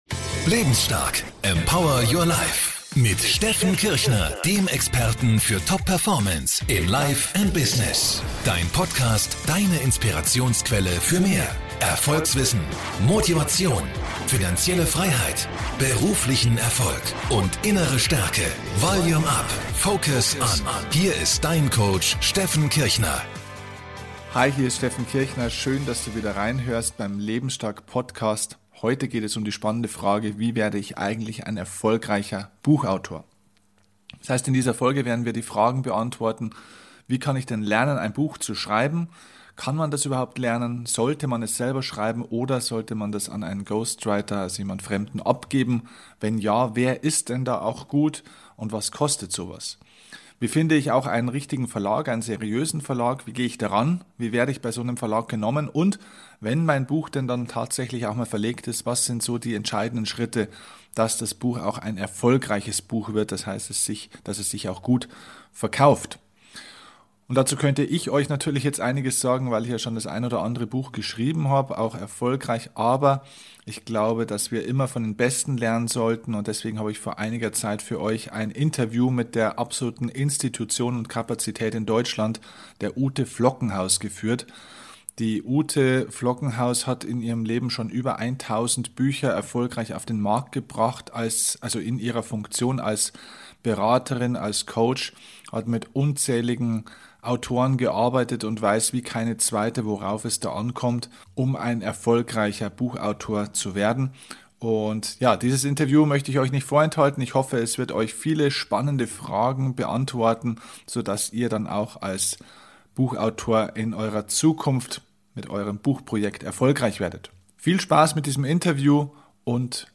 In diesem Interview mit mir, teilt sie für Dich ihr Wissen, wie Du es schaffen kannst, ein erfolgreicher Buchautor zu werden.